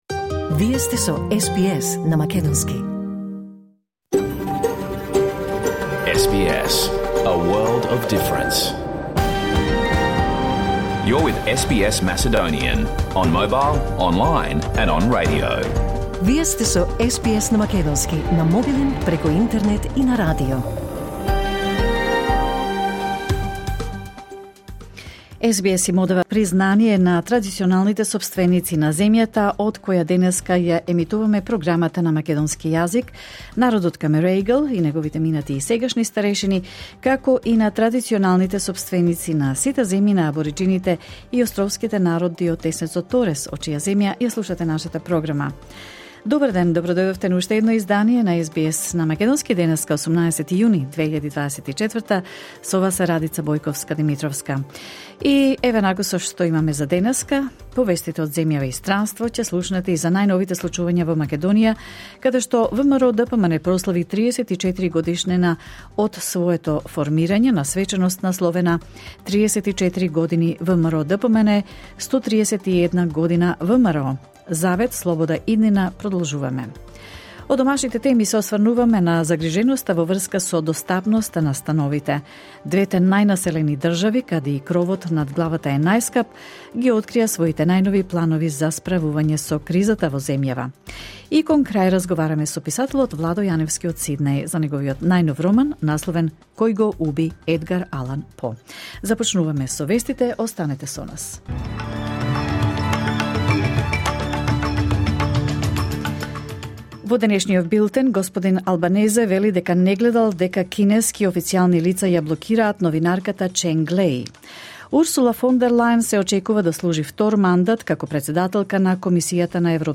SBS Macedonian Program Live on Air 18 June 2024